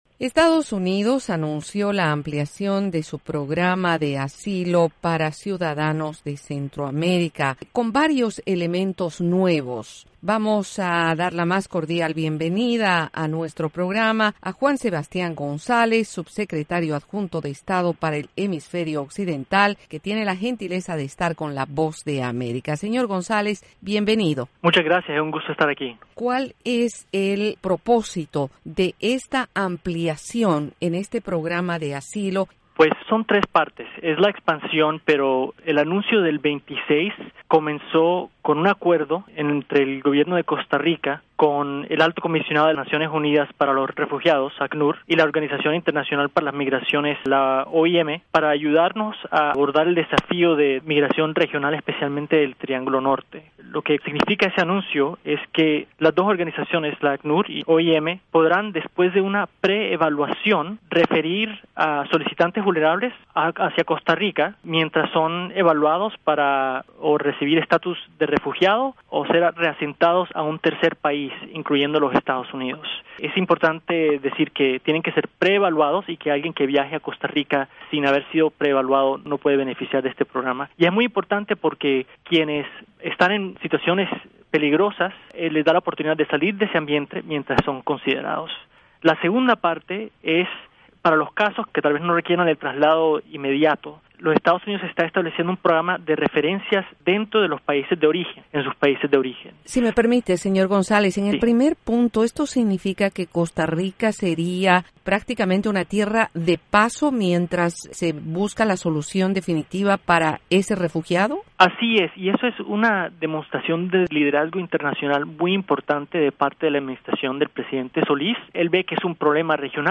Juan Sebastián González dialoga sobre el programa de asilo de EE.UU. para Centroamérica